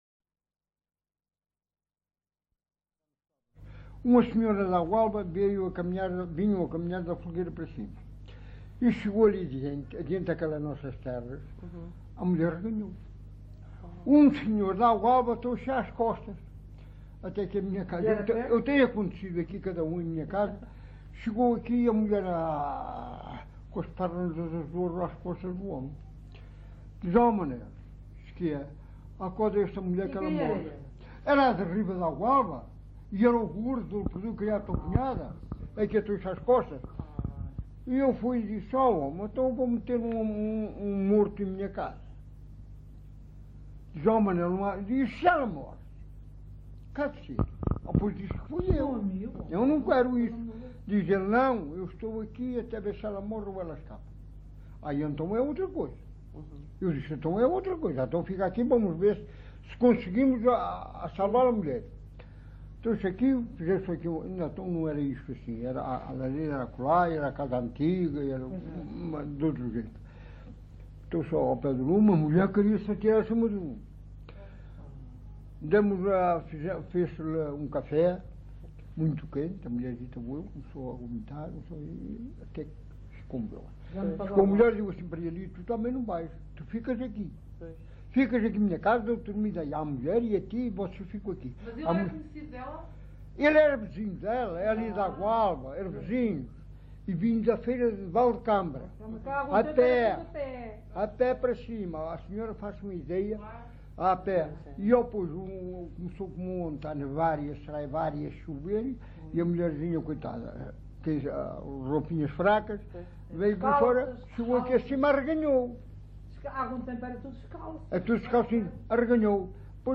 LocalidadeCovo (Vale de Cambra, Aveiro)